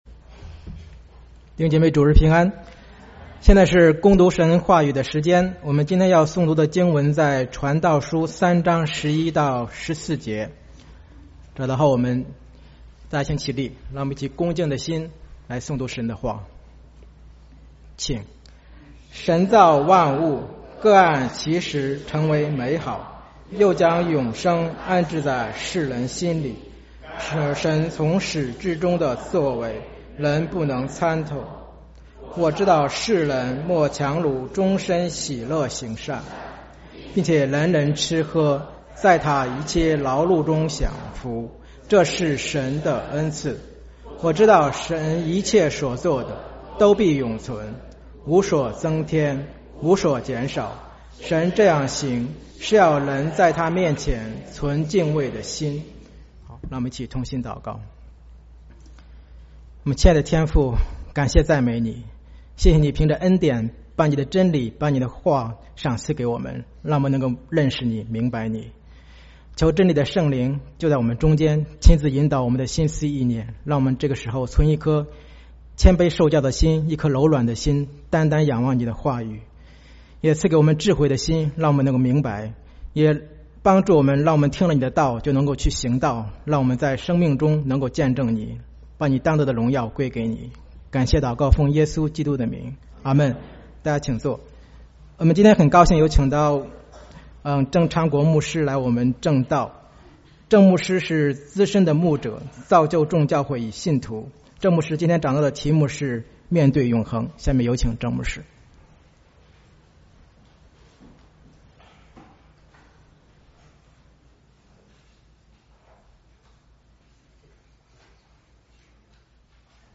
2016 主日證道